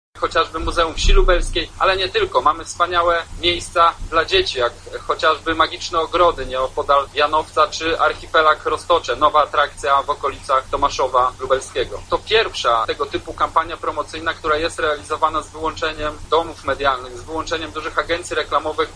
– mówi wicemarszałek województwa Michał Mulawa.